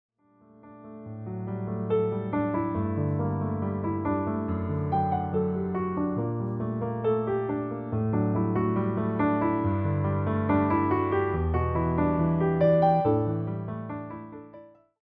give the album a quiet, peaceful balance